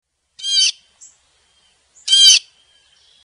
_the sound wave diagram of the Blue Jay is also triangle shaped
sound sample of Blue Jay
blueJaySound.mp3